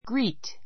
ɡríːt